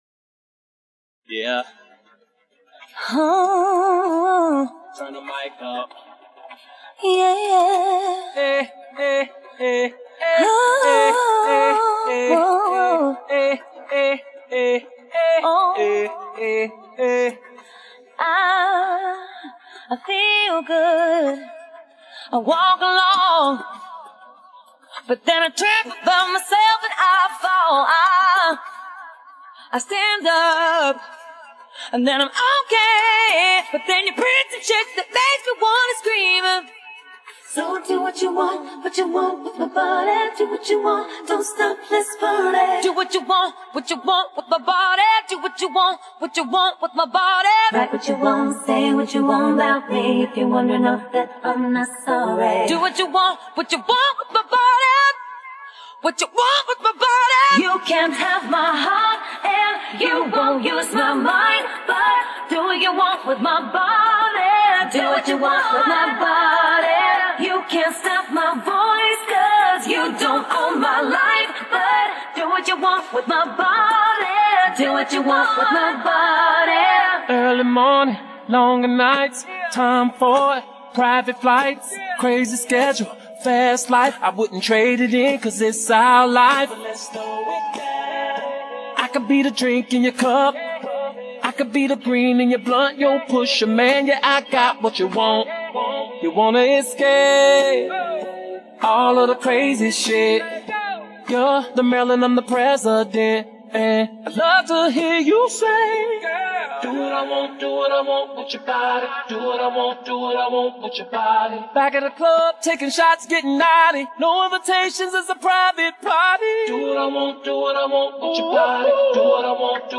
Категория: Скачать Зарубежные акапеллы